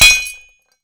grenade_hit_metal_02.WAV